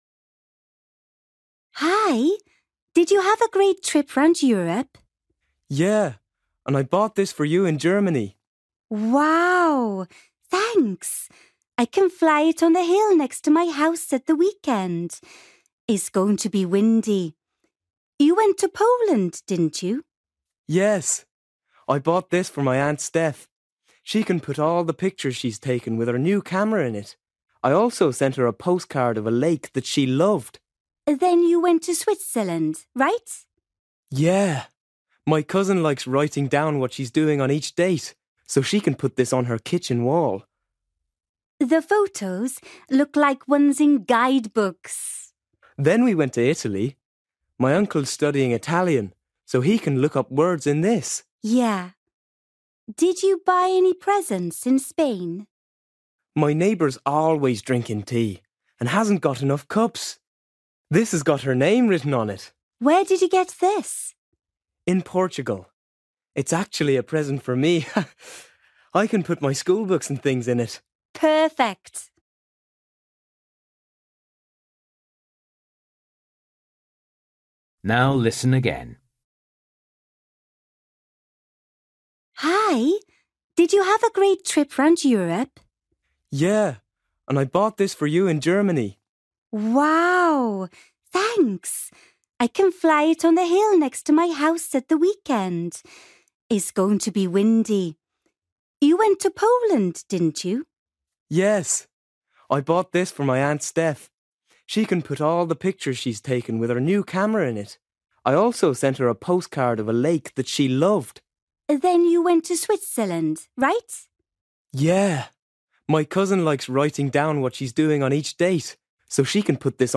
You will hear a boy talking to a friend about the presents he bought during a family trip round Europe.